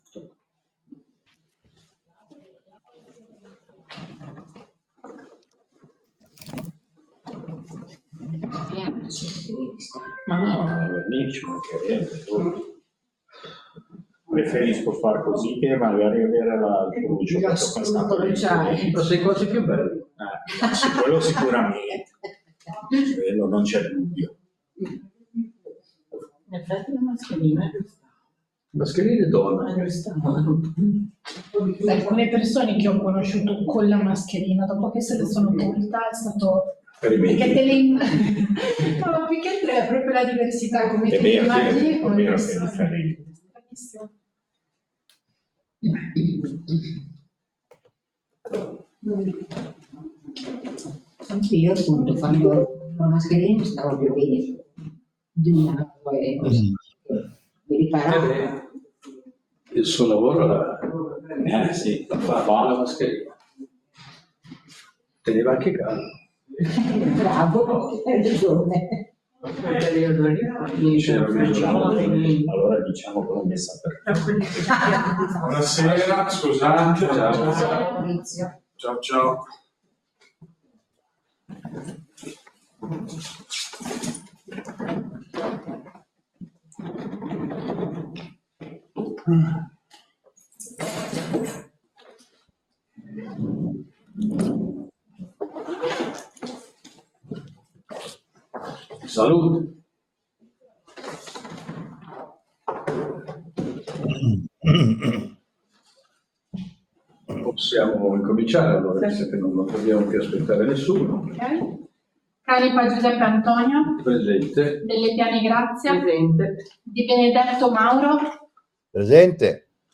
Seduta del Consiglio Comunale del 29/12/2025